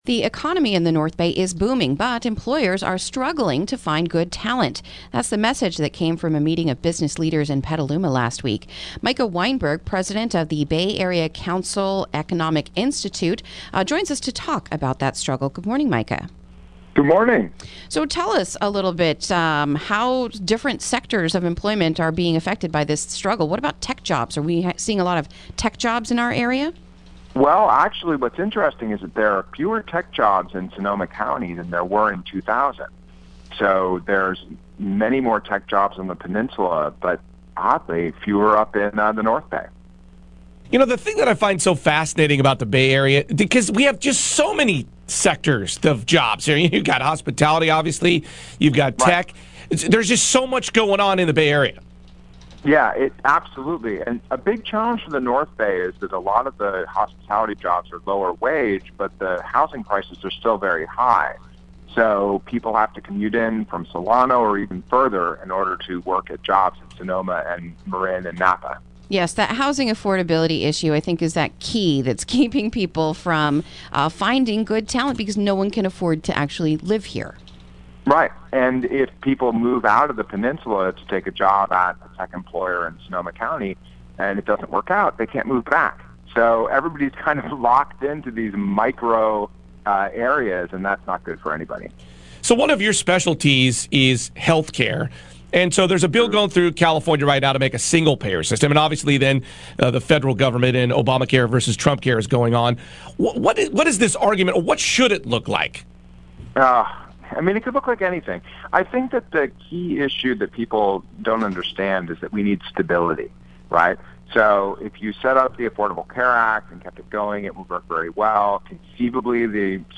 Interview: North Bay Jobs Will Countinue to Go Begging | KSRO 103.5FM 96.9FM & 1350AM